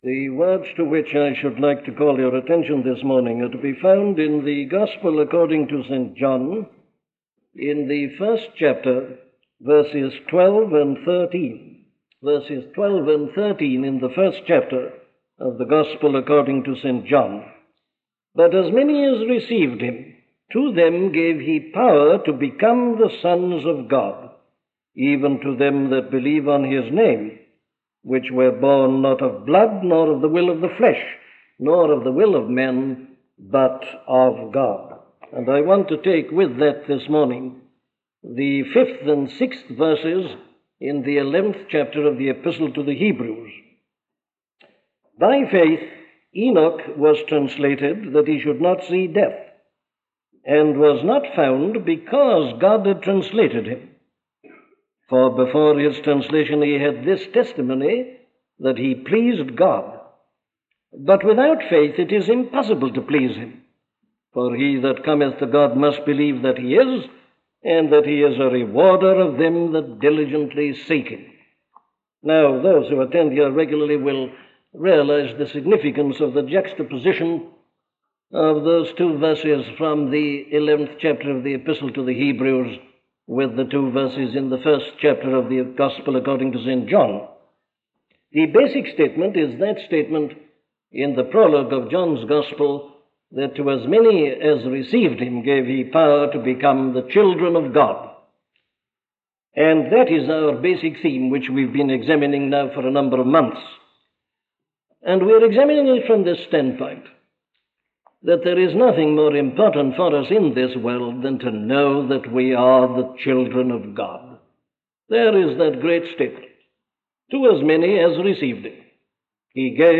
To Walk with God - a sermon from Dr. Martyn Lloyd Jones